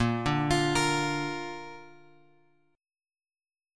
A# chord {6 5 x x 6 6} chord
Asharp-Major-Asharp-6,5,x,x,6,6-8.m4a